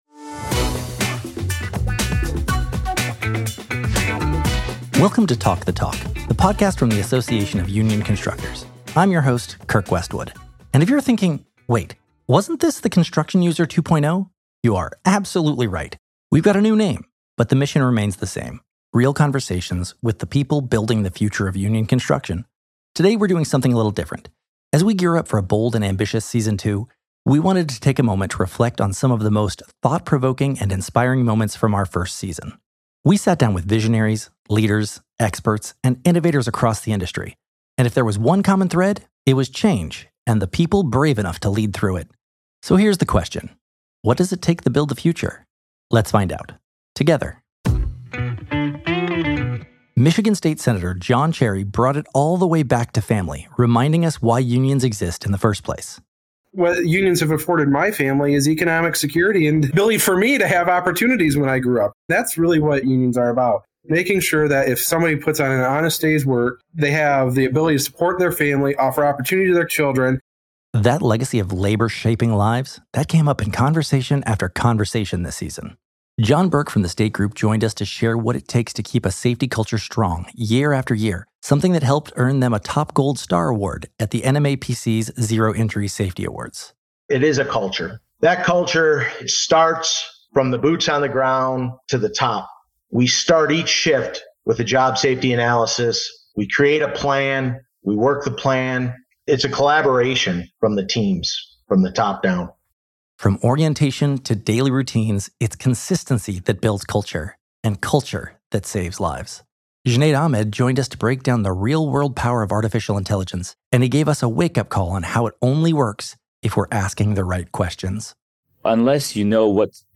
• (02:16) Geoff Colvin discusses business reimagination and future-proofing